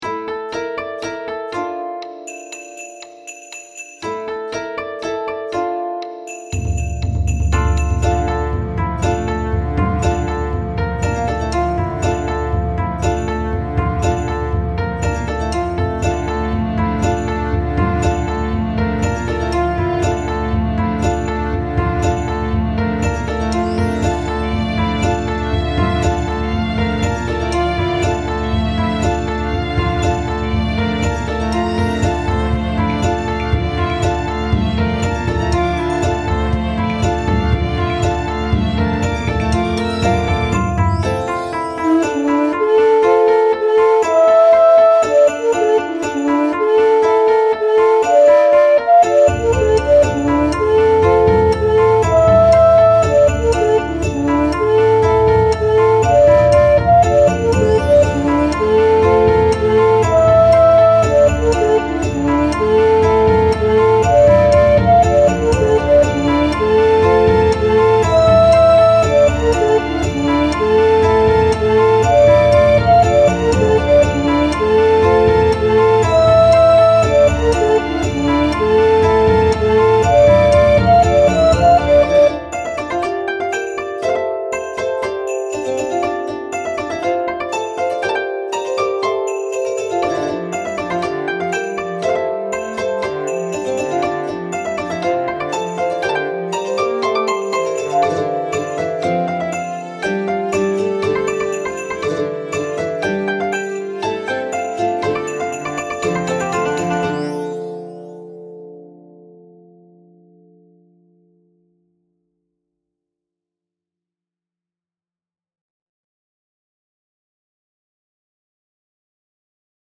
BGM
ロング暗い民族